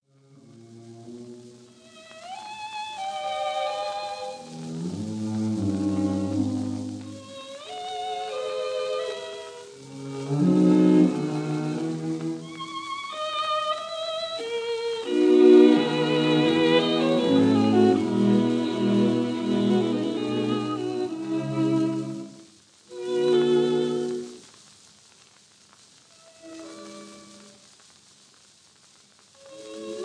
1st violin
2nd violin
viola
cello
2nd viola